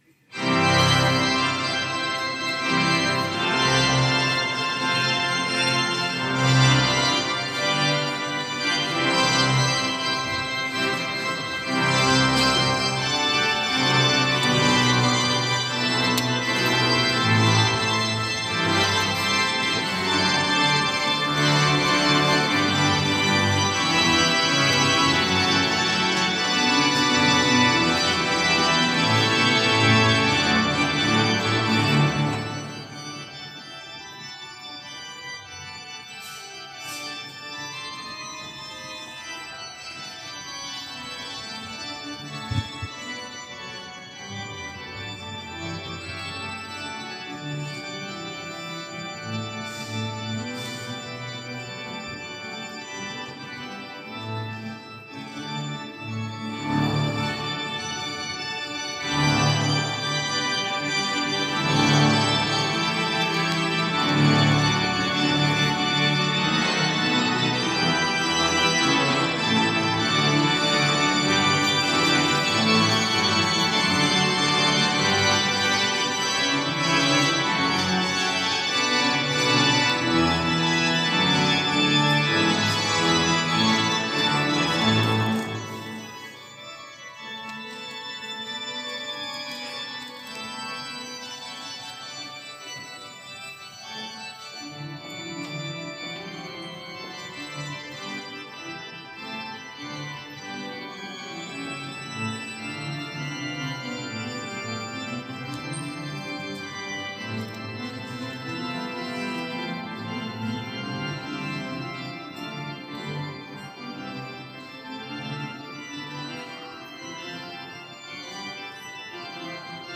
Culte du lundi 25 décembre 2017